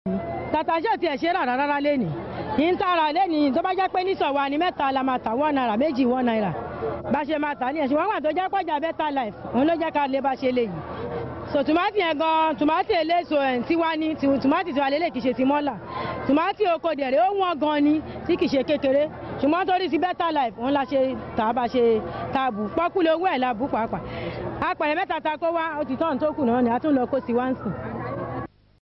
Throwback To The Nigerian Market